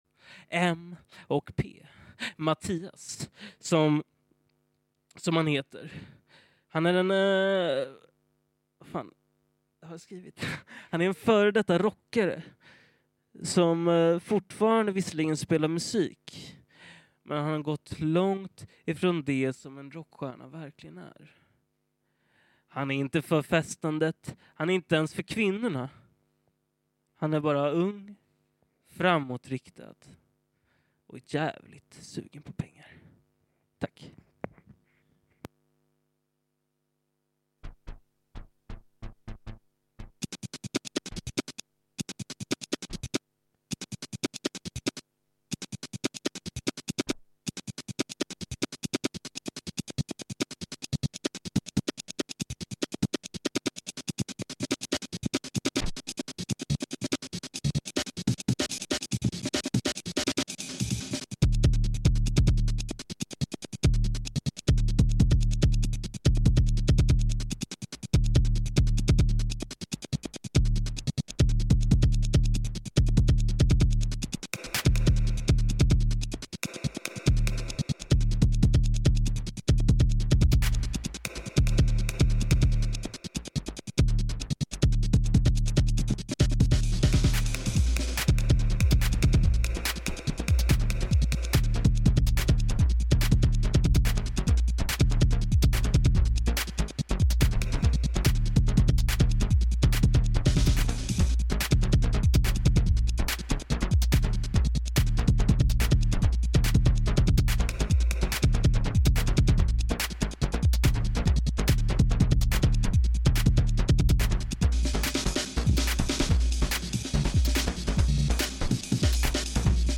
Live from Pacesetters Kitchen Radio (Malaga, Spain)
Live from Pacesetters Kitchen Radio (Malaga, Spain): Basspistol Radio (Audio) Nov 27, 2025 shows Live from Pacesetters Kitchen Radio (Malaga, Spain) Basspistol Radio Station! 777% without commercials! RobotDJ-sets and live interventions!